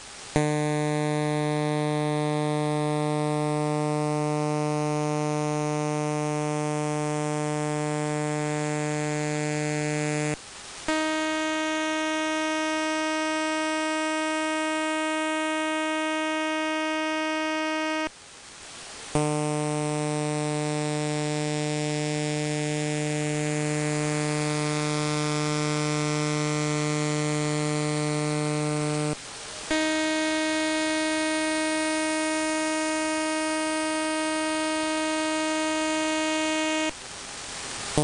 Ghadir radar, low+medium pulse freq
Ghadir_low_medium_rate_AM.ogg